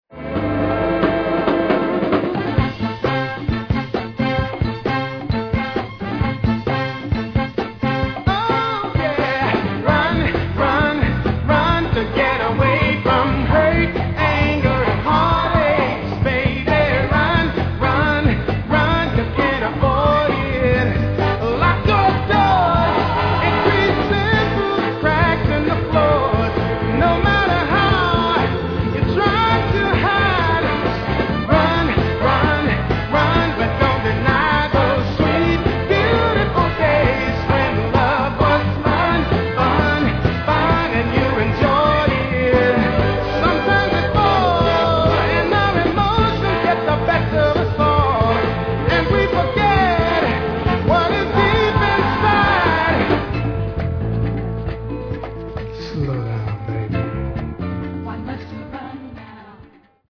#94.1 (Northern Soul 500)